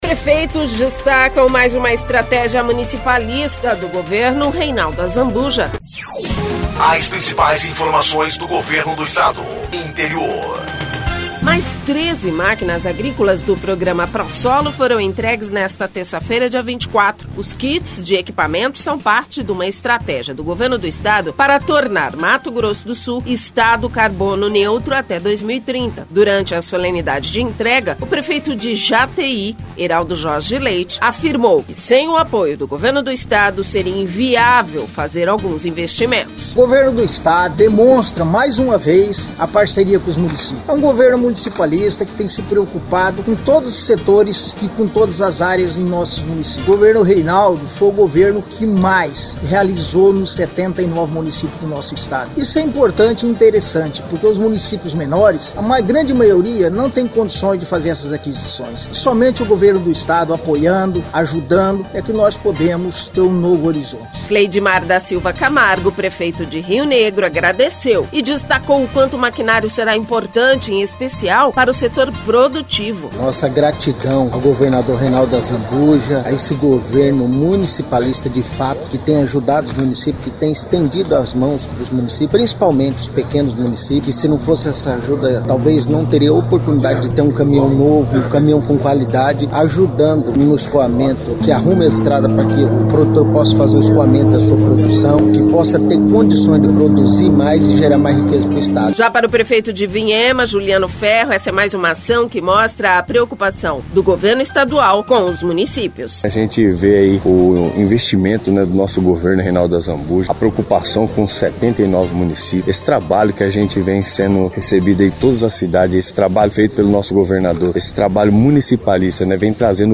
Durante a solenidade de entrega, o prefeito de Jateí, Eraldo Jorge Leite, sem o apoio do Governo do Estado seria inviável fazer alguns investimentos.
Cleidimar da Silva Camargo, prefeito de Rio Negro, agradeceu e destacou o quanto o maquinário será importante, em especial para o setor produtivo.